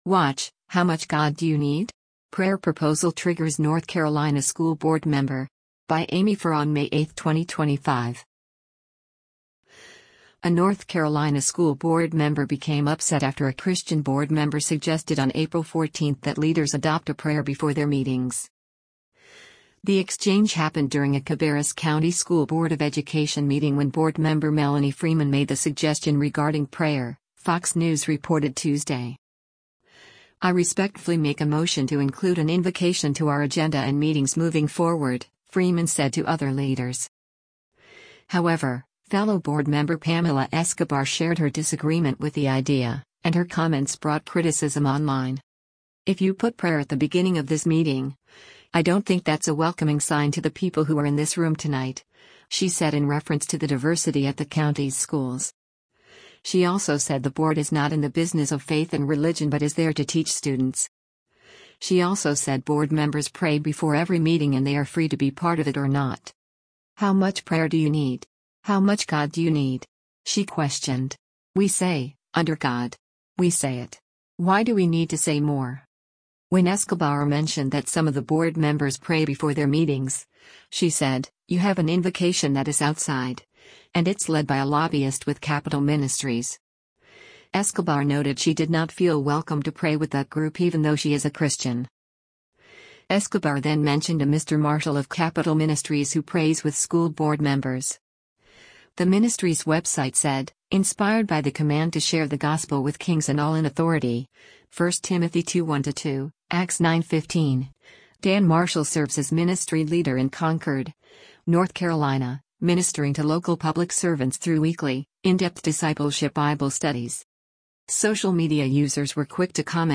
The exchange happened during a Cabarrus County School Board of Education meeting when board member Melanie Freeman made the suggestion regarding prayer, Fox News reported Tuesday.